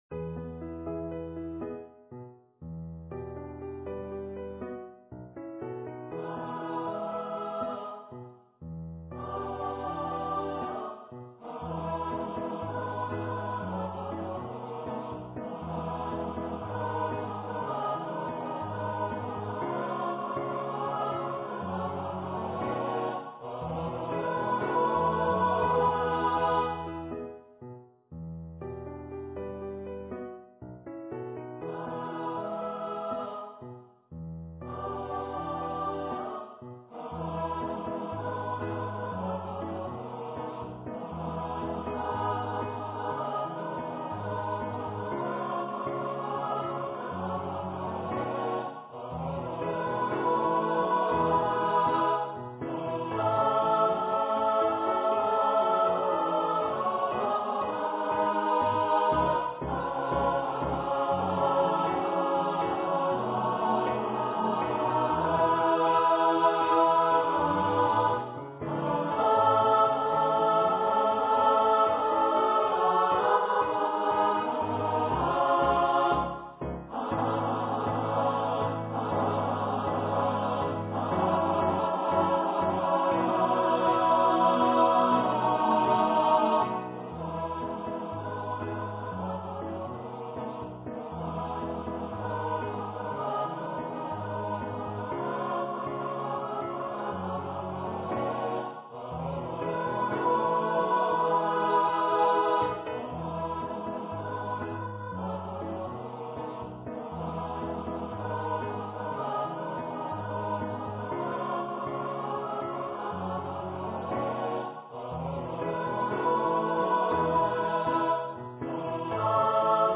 for SA+Men choir
for choir and orchestra or piano
Choir - Mixed voices (SAMen)